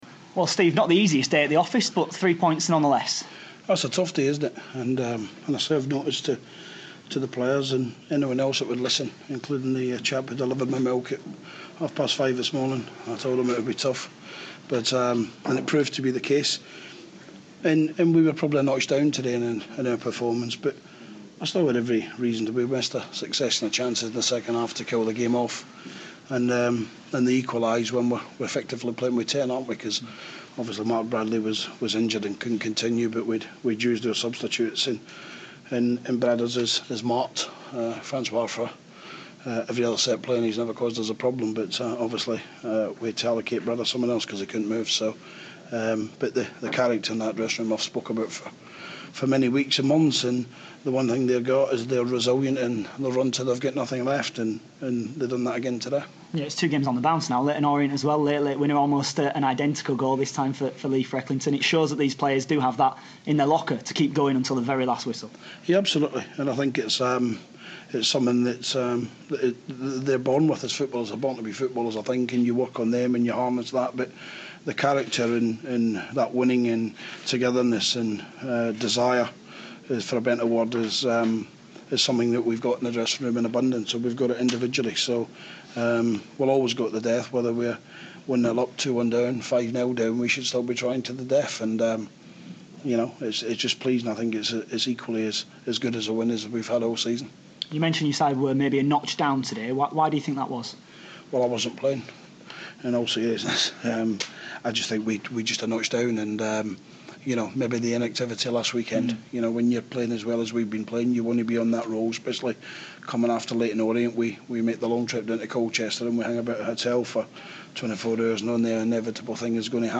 Rotherham manager Steve Evans after a 2-1 win over Stevenage